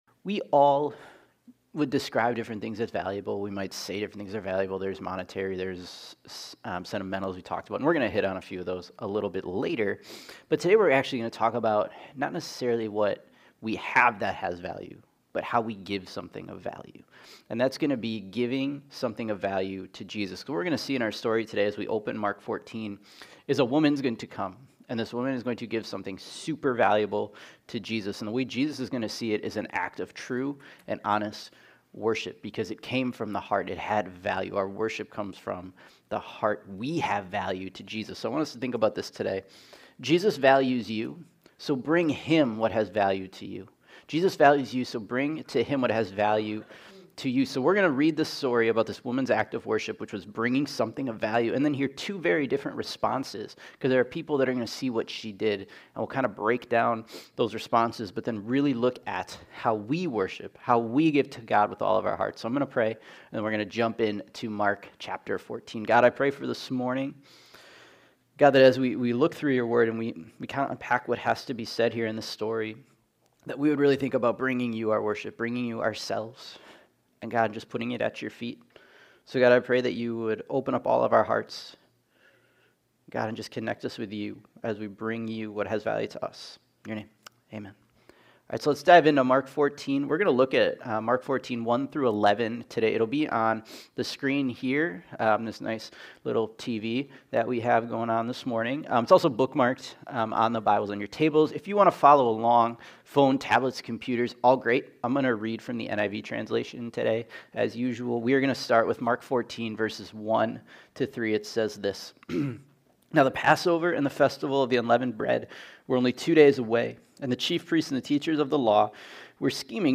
Mark Home Judgement Life Money Seize the Moment Value Worship Worthy Sunday Morning In the opening section of Mark 14 we hear the story of a woman anointing Jesus with perfume.